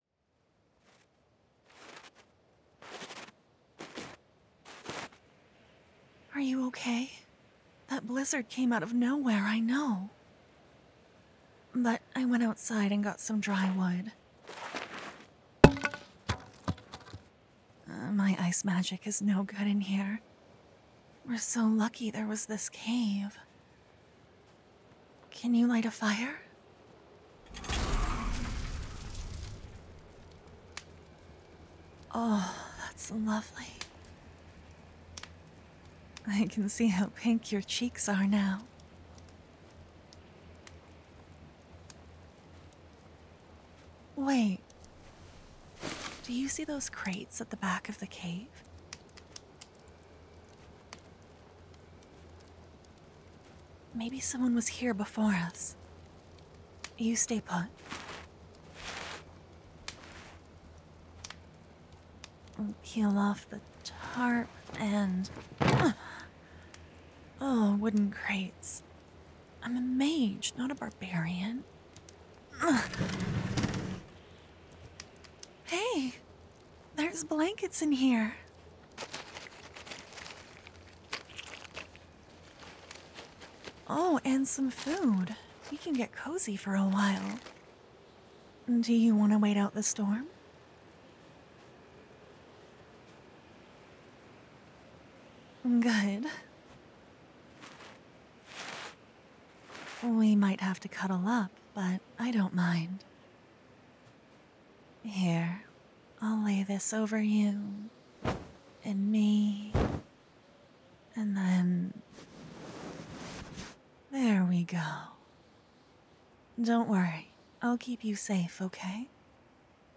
Edit: Messed with the sound levels, the ambience was very quiet~